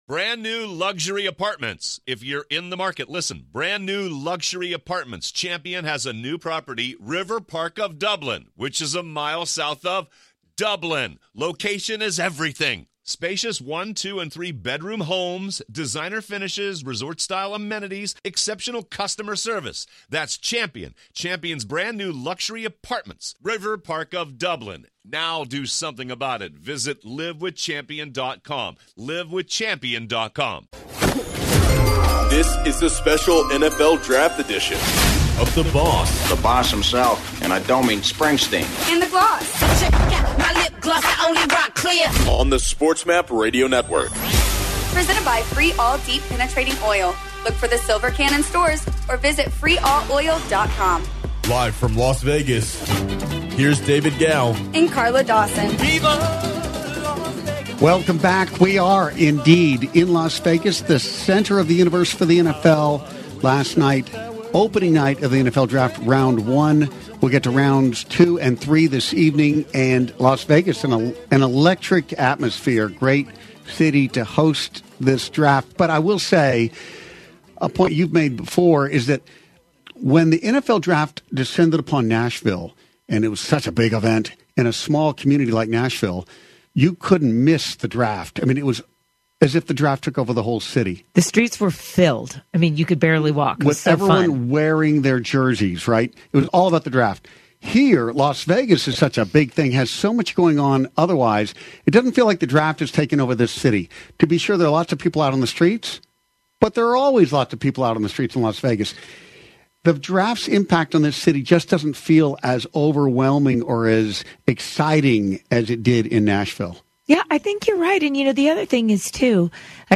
02/08/23 The Boss and The Gloss LIVE from Media Row in Arizona Hour 2
American actress, model, television personality and sportscaster, Bonnie-Jill Laughlin, joined The Boss and the Gloss on Radio Row in Arizona for Super Bowl 57